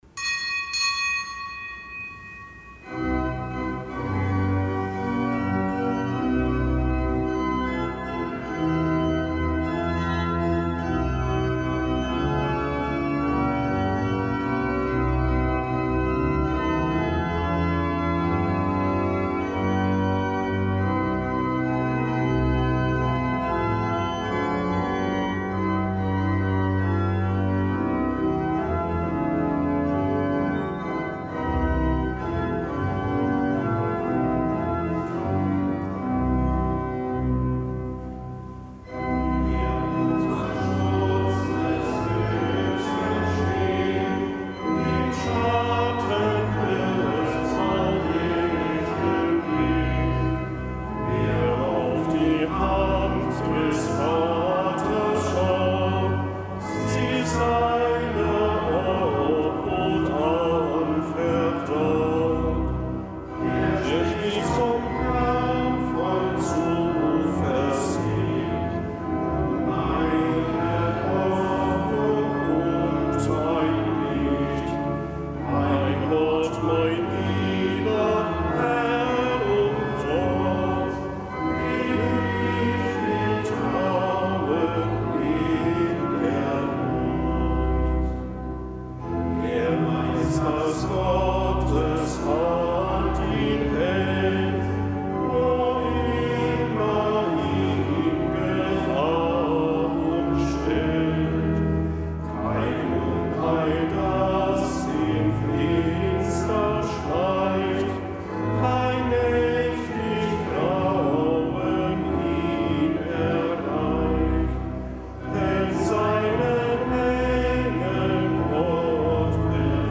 Kapitelsmesse am Herz-Jesu-Freitag
Kapitelsmesse aus dem Kölner Dom am Freitag der vierten Woche im Jahreskreis, Herz-Jesu-Freitag.